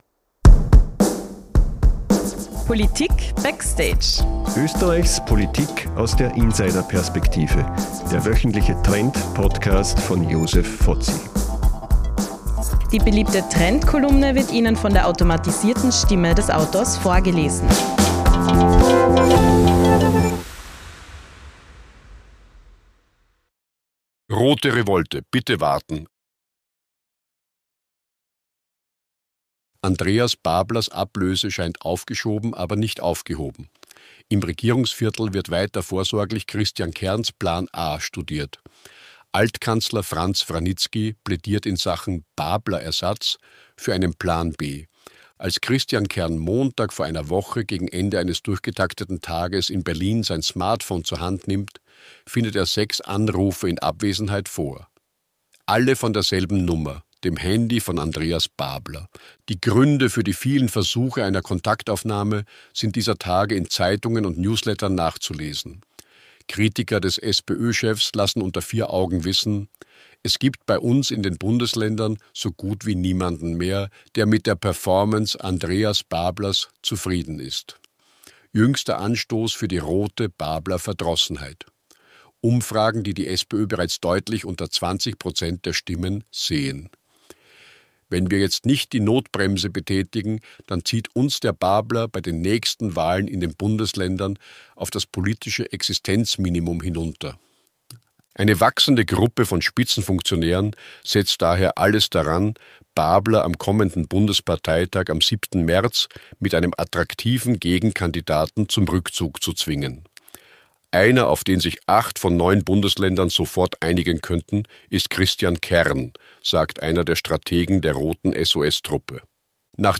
Wie jede Woche erzählt Ihnen die KI-generierte Stimme von